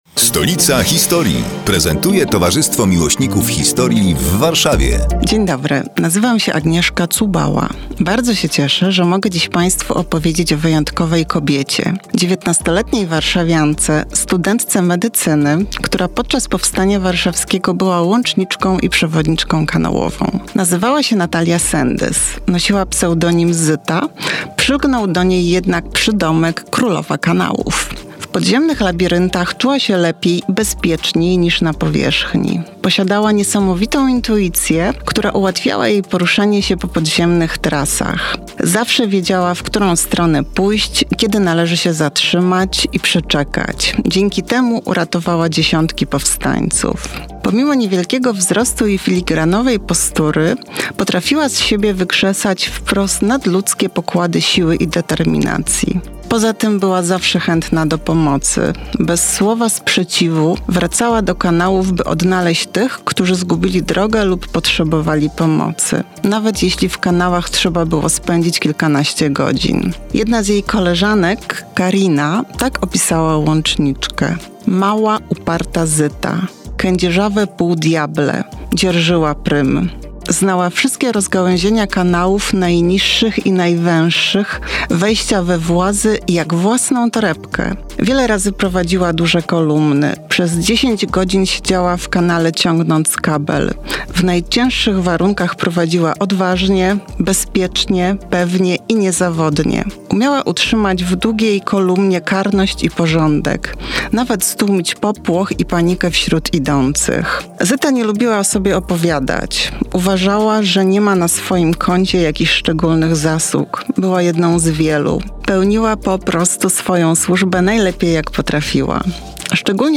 Przedstawiają członkowie Towarzystwa Miłośników Historii w Warszawie, które są emitowane w każdą sobotę o 15:15, w nieco skróconej wersji, w Radiu Kolor.